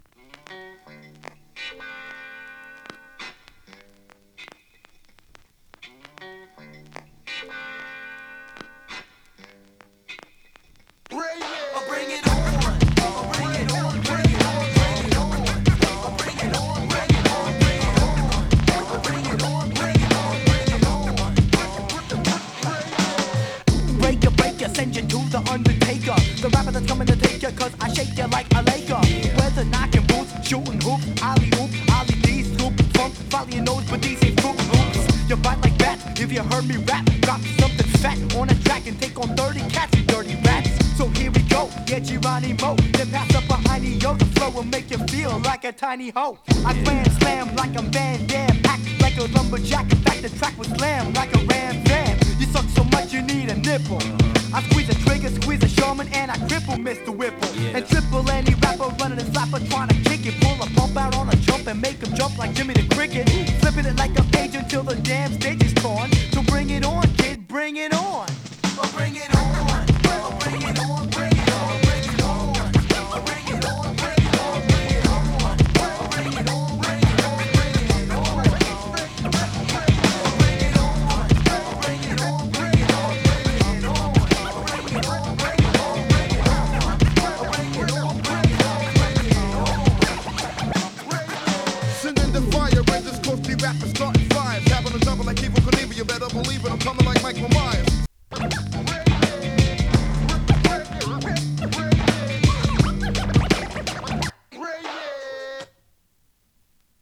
Vocal 4.12)　頭のノイズは元々のプロダクションによるものです。